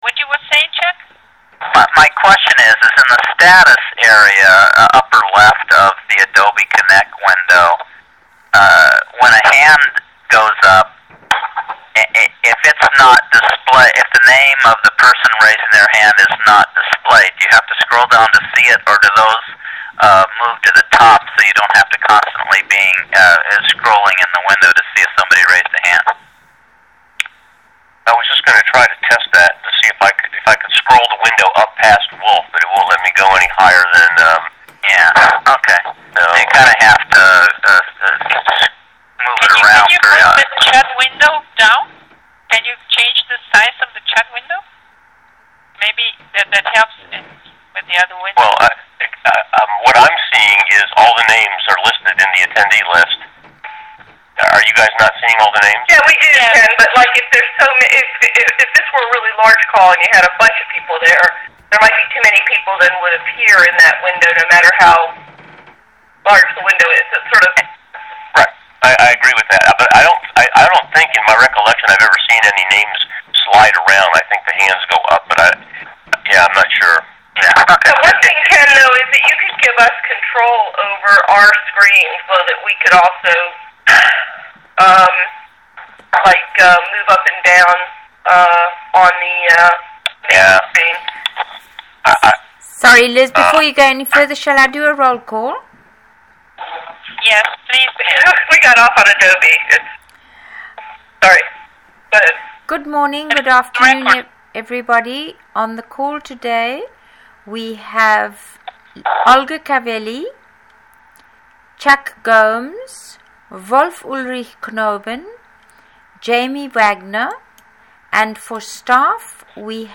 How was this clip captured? [gnso-wpm-dt] MP3 GNSO Work Prioritization Model call - 10.12.09 at 2000 UTC